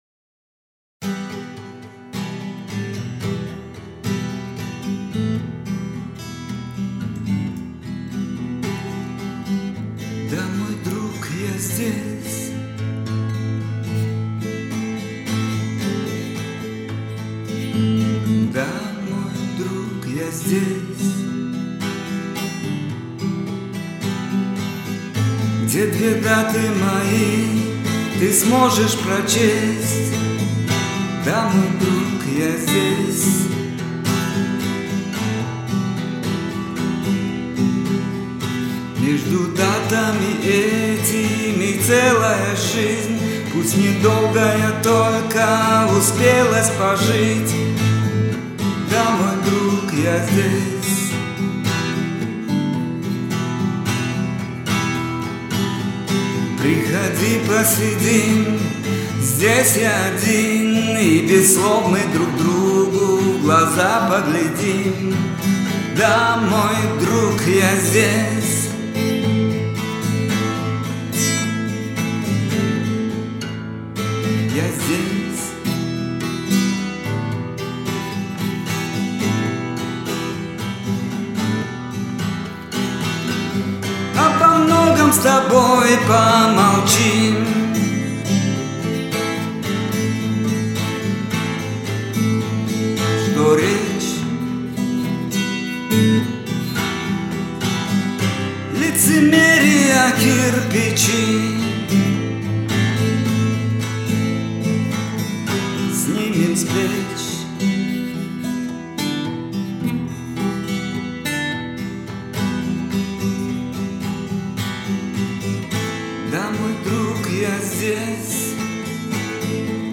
пиано